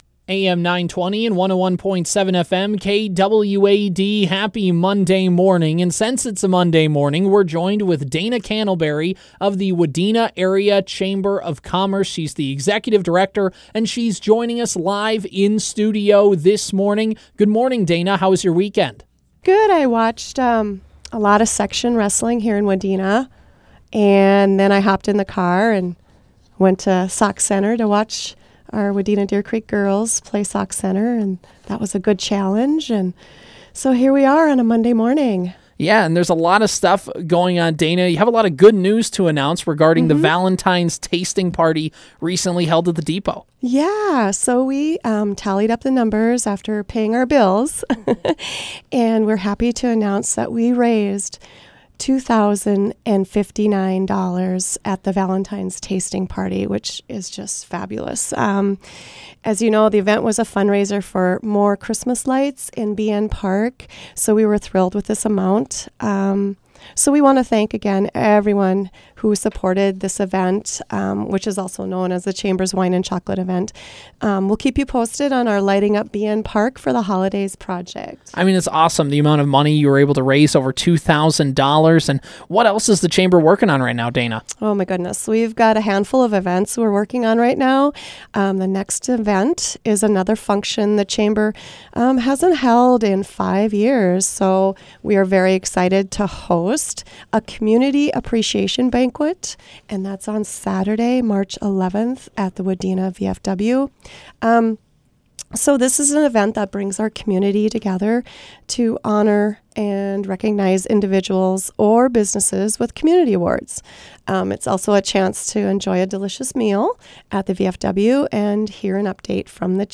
stopped in studio to go over all that’s happening with the Wadena Chamber.